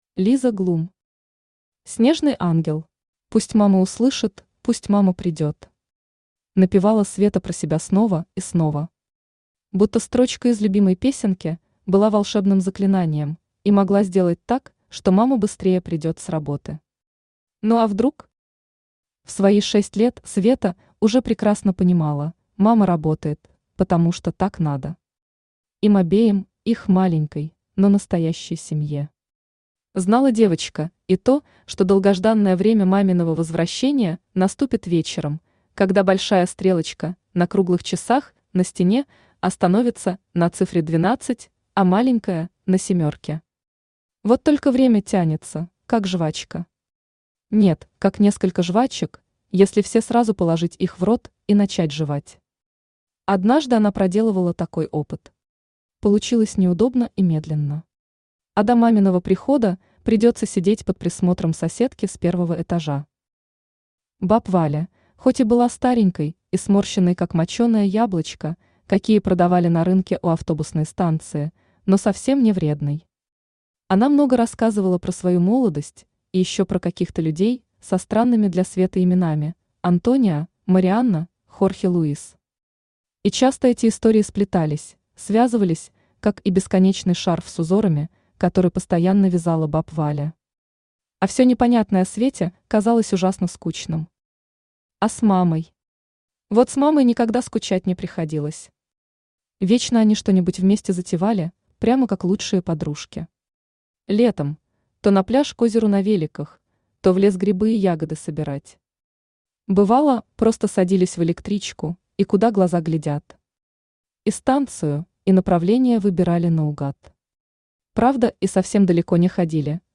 Аудиокнига Снежный ангел | Библиотека аудиокниг
Aудиокнига Снежный ангел Автор Лиза Глум Читает аудиокнигу Авточтец ЛитРес.